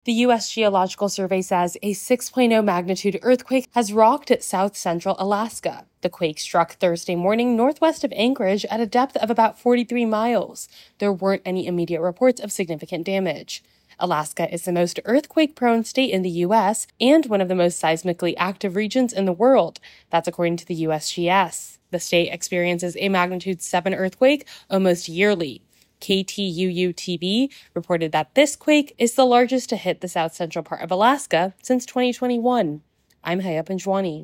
reports on an earthquake in Alaska.